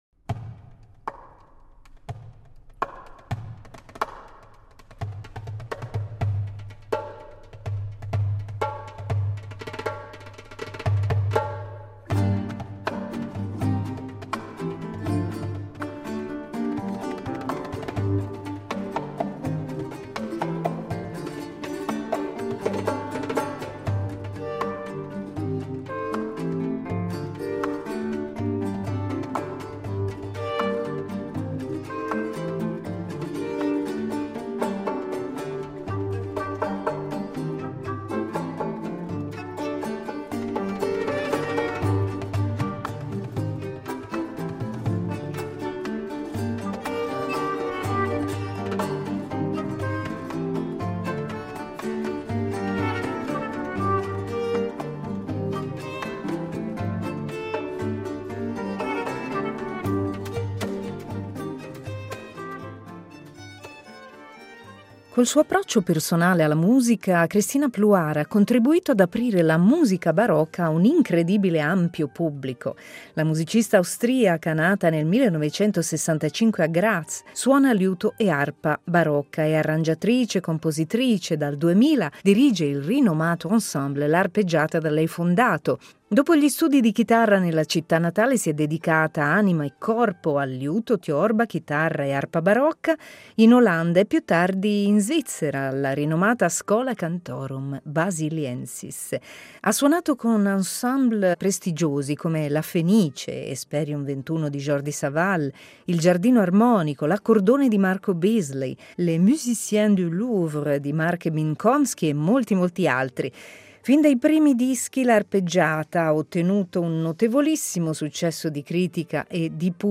Musicalbox